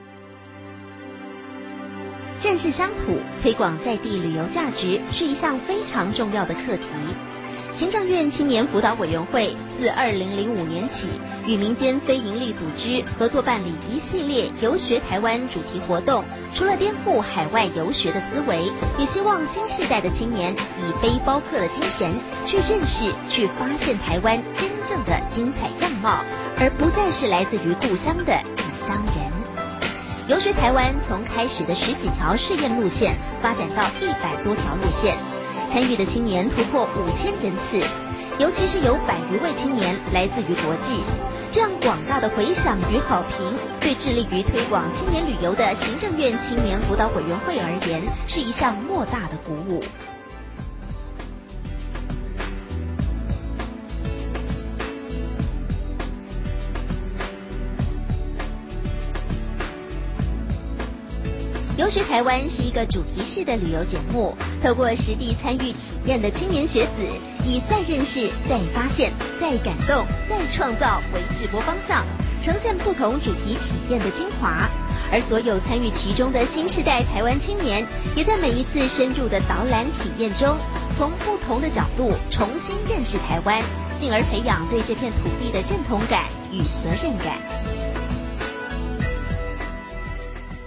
台語配音 國語配音 女性配音員 客語配音
(旁白-新聞性 國)-遊學台灣
她亦擅長年輕女性、母性角色及莊重旁白語調，廣受廣播劇與政府標案製作青睞。
旁白-新聞性-國-遊學台灣.mp3